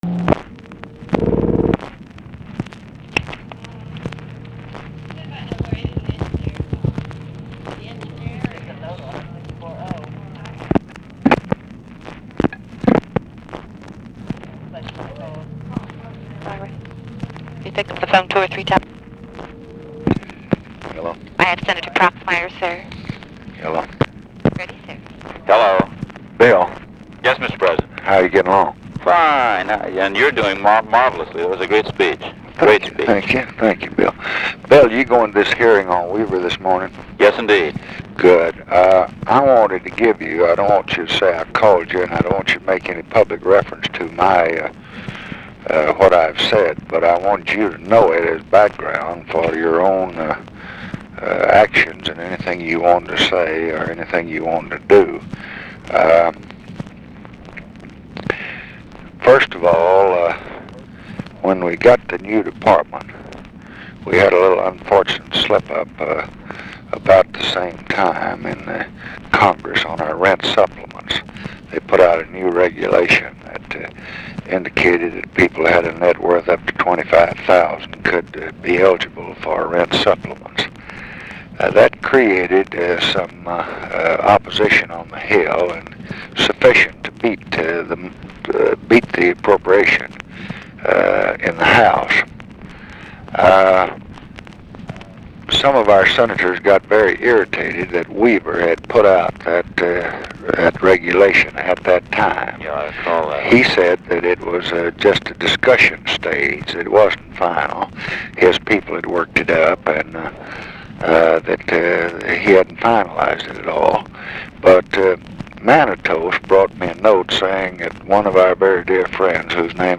Conversation with WILLIAM PROXMIRE, January 17, 1966
Secret White House Tapes